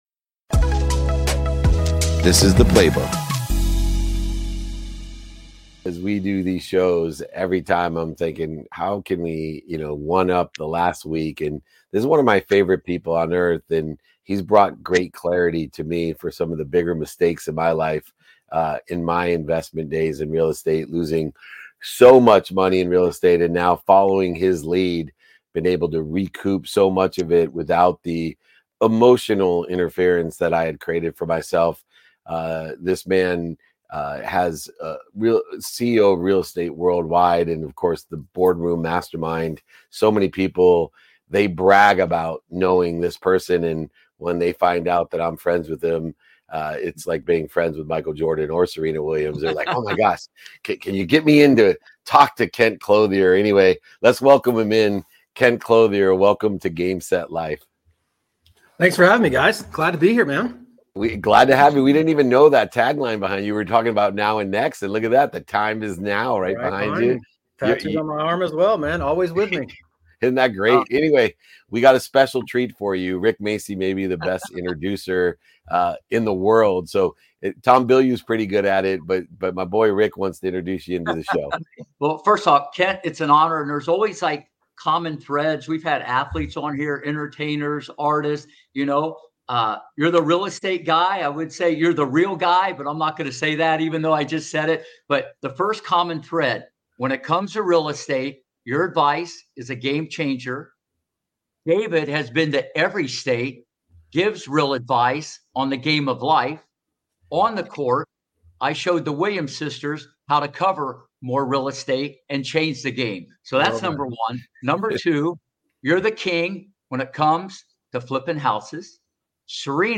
There is No Better Time Than Now: A Conversation